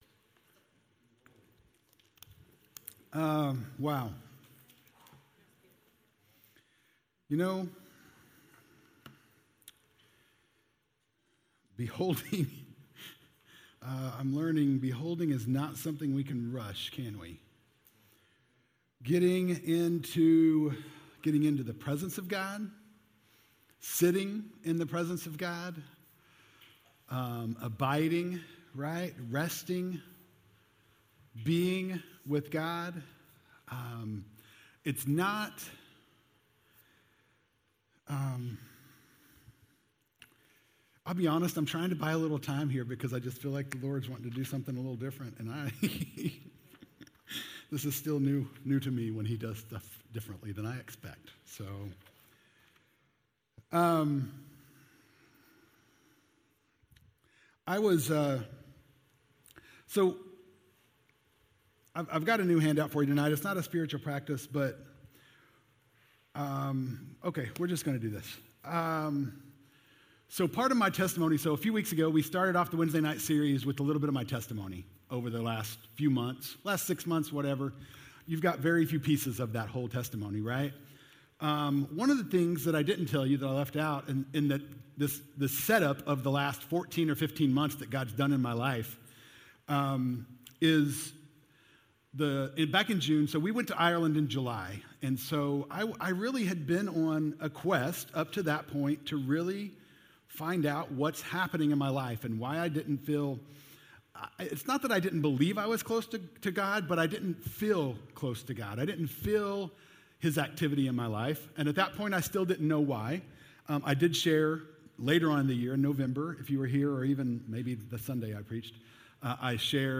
Sermons | Grace Pointe Church